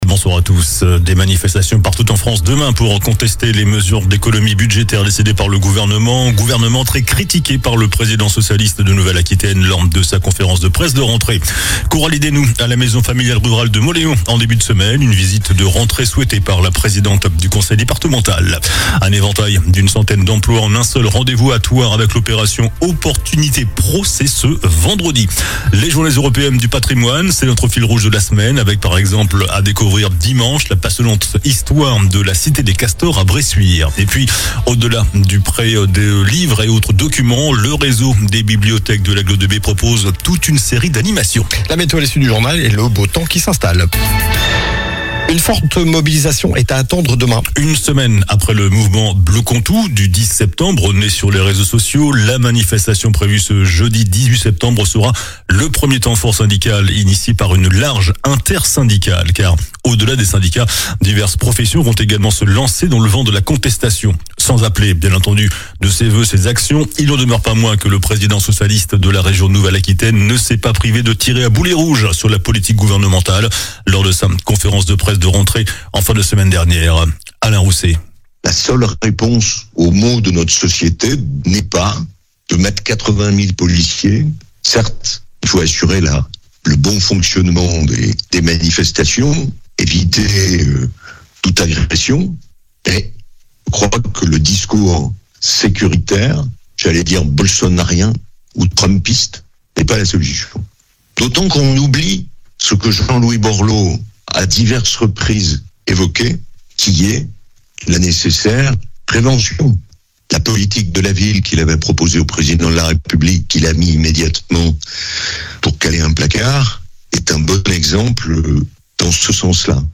JOURNAL DU MERCREDI 17 SEPTEMBRE ( SOIR )